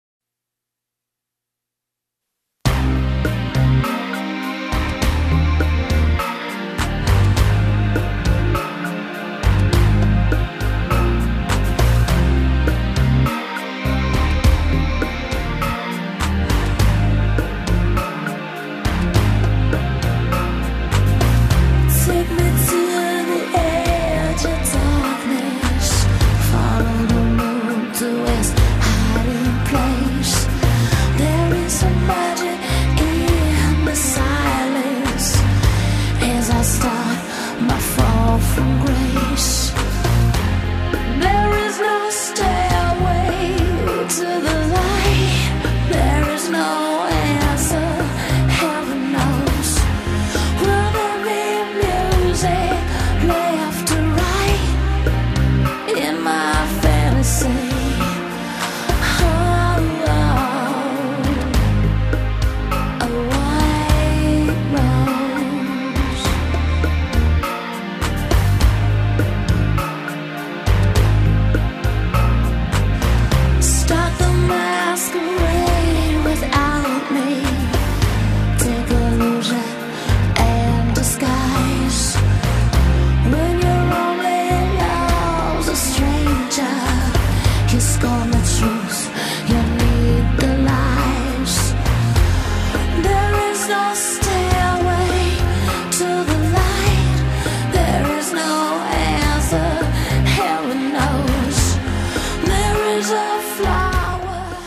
slower, more sensual version